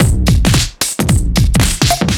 OTG_DuoSwingMixB_110b.wav